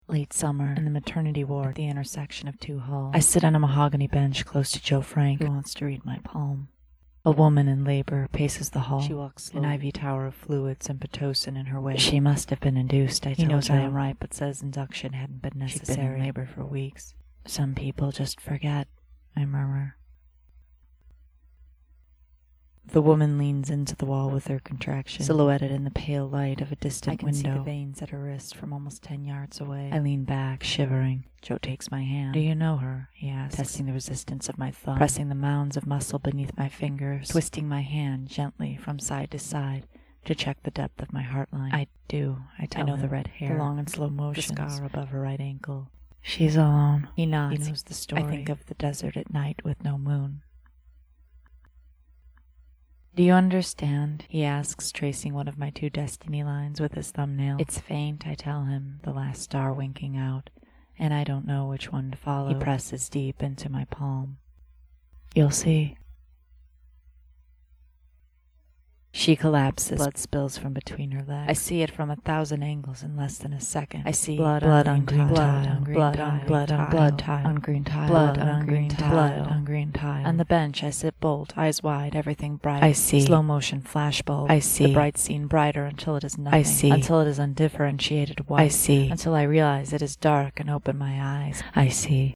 Short Form Storytelling